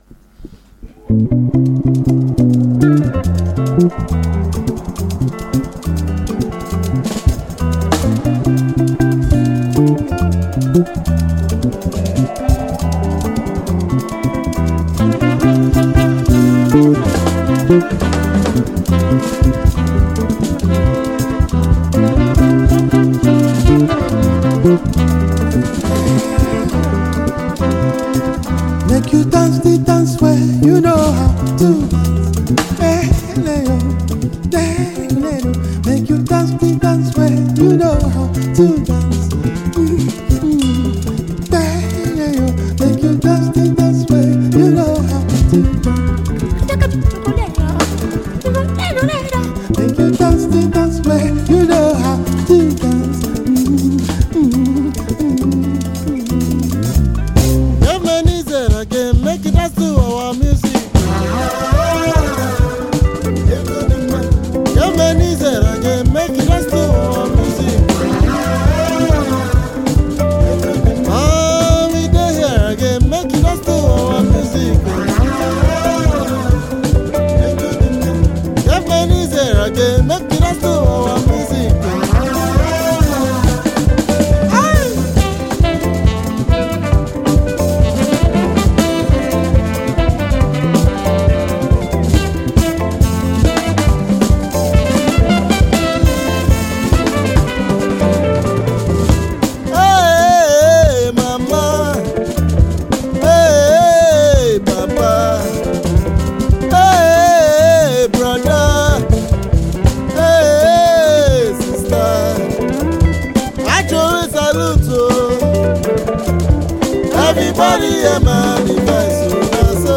Captivating Nigerian highlife musical duo